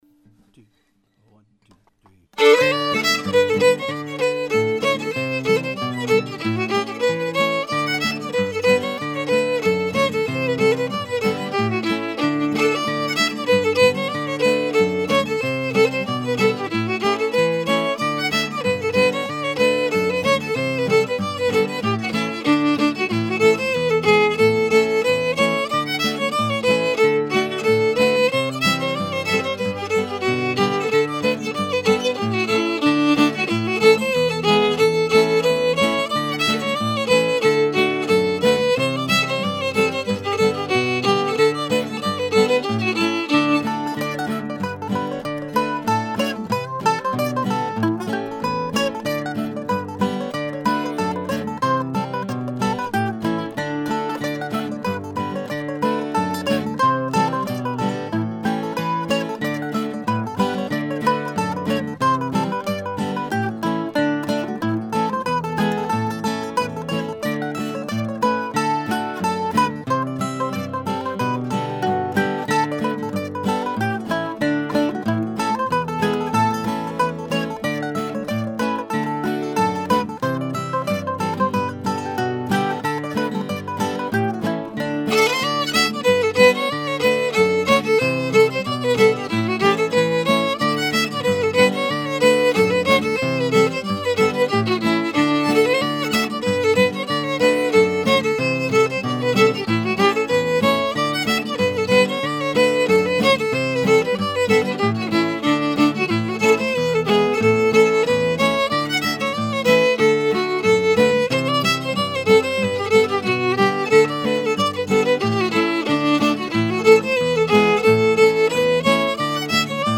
And so I diddled it out as a reel in 2/4 time as I drove down the road.
The A strain quotes the opening motif and closing resolve of Luther’s first line. But it has an entirely unique turn of phrase.
fiddle, mandolin & guitar